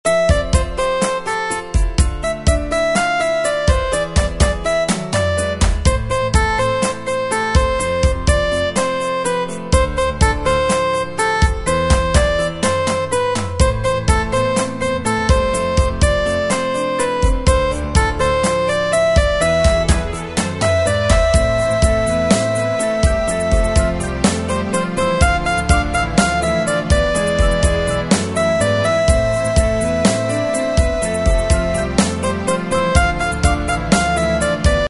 Tempo: 124 BPM.
MP3 with melody DEMO 30s (0.5 MB)zdarma